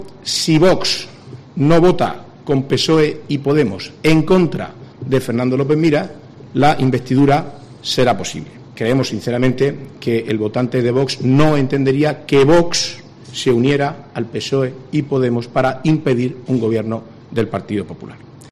Marcos Ortuño, portavoz del gobierno regional en funciones
Ortuño ha hecho estas declaraciones a preguntas de los periodistas durante la rueda de prensa celebrada tras reunión semanal del Consejo de Gobierno, que ha tenido lugar en el Palacio de San Esteban, sede del Ejecutivo murciano.